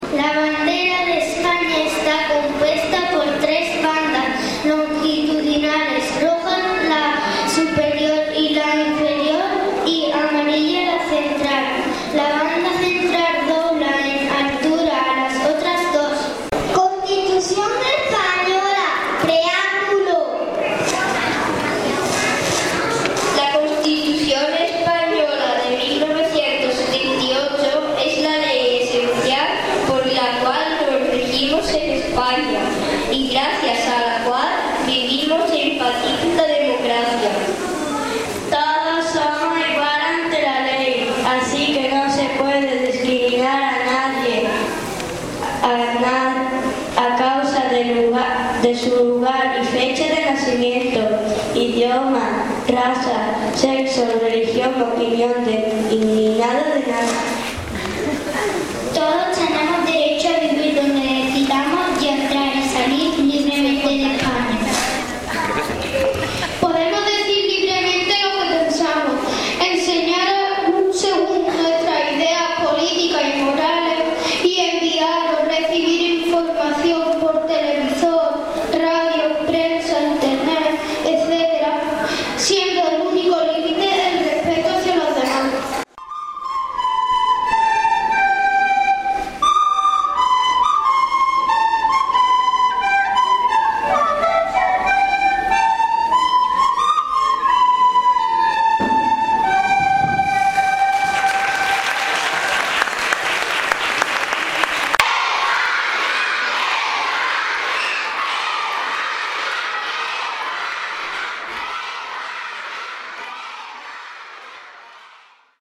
Audio: alcalde | mp3